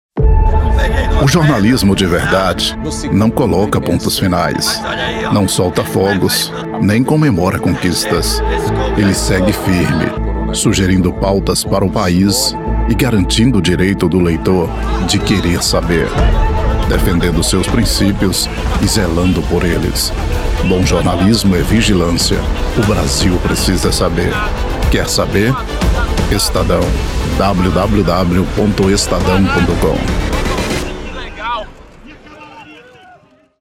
Male
Yng Adult (18-29), Adult (30-50)
Profissional da voz que atende vários projetos de áudio.
Narration
All our voice actors have professional broadcast quality recording studios.